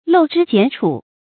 鏤脂翦楮 注音： ㄌㄡˋ ㄓㄧ ㄐㄧㄢˇ ㄔㄨˇ 讀音讀法： 意思解釋： 雕刻油脂，剪裁楮葉。喻徒勞無益。